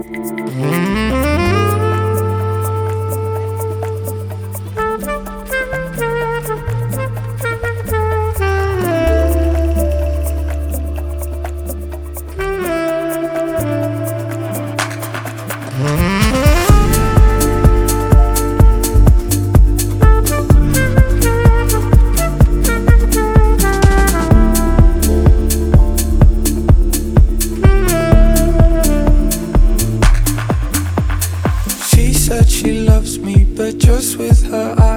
Жанр: Поп / Электроника